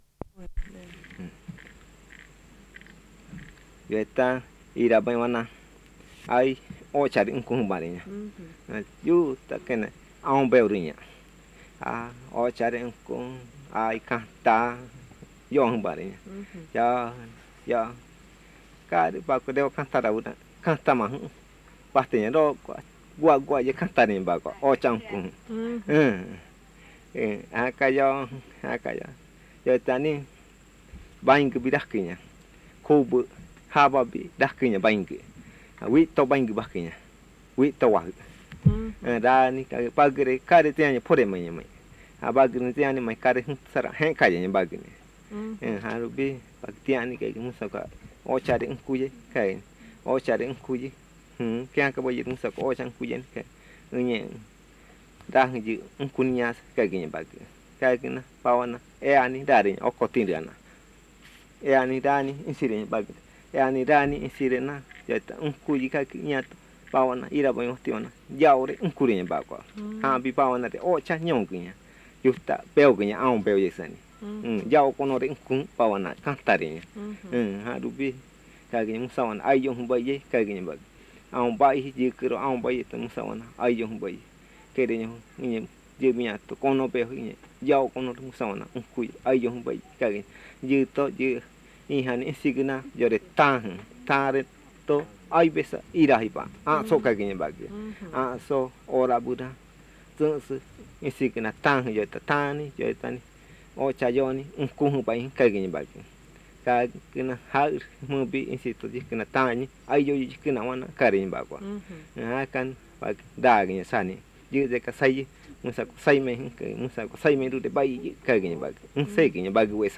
Buenavista, río Putumayo (Colombia)